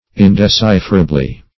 Search Result for " indecipherably" : The Collaborative International Dictionary of English v.0.48: Indecipherable \In`de*ci"pher*a*ble\, a. Not decipherable; incapable of being deciphered, explained, or solved.